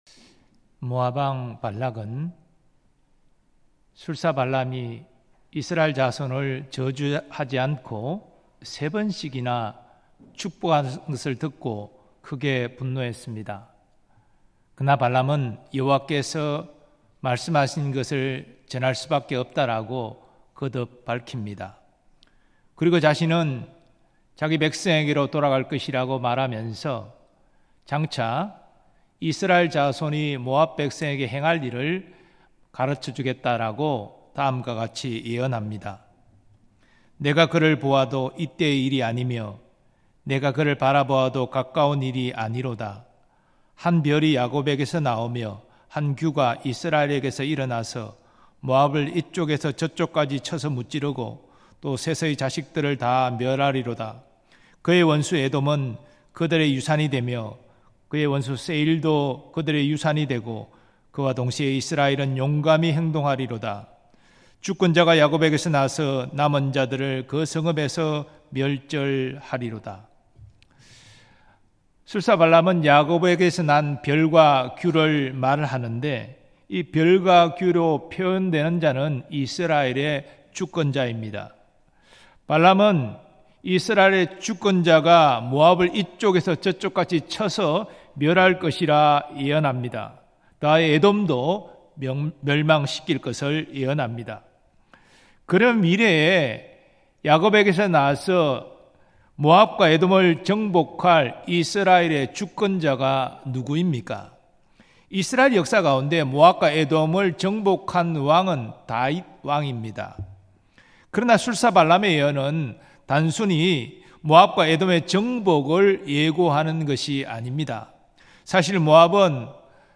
삼일예배
온 세상의 주관자 되시는 하나님 음성설교 듣기 MP3 다운로드 목록 이전 다음